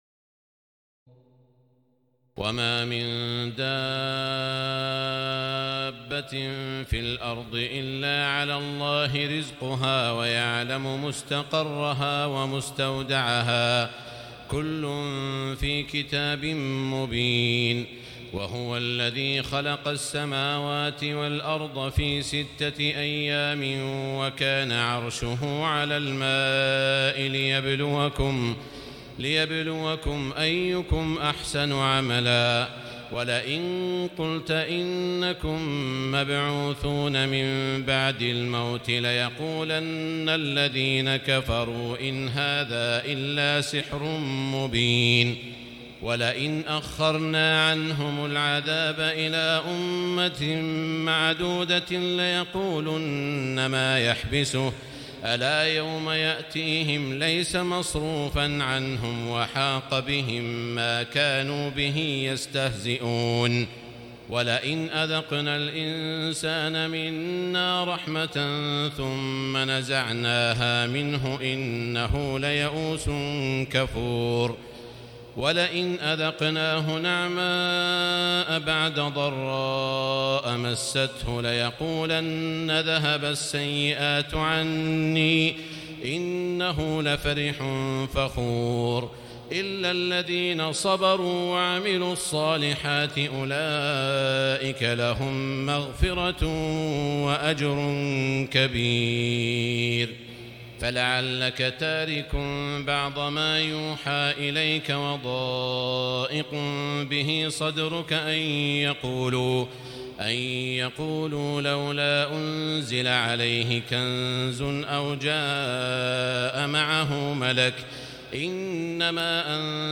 تراويح الليلة الحادية عشر رمضان 1439هـ من سورة هود (6-83) Taraweeh 11 st night Ramadan 1439H from Surah Hud > تراويح الحرم المكي عام 1439 🕋 > التراويح - تلاوات الحرمين